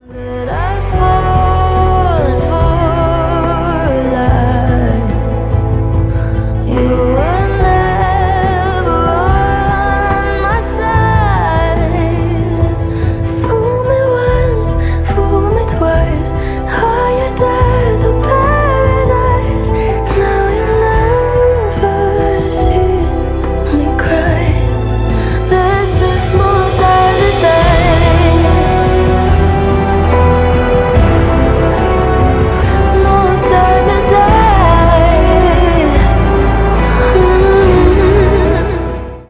Без слов
Big beat Транс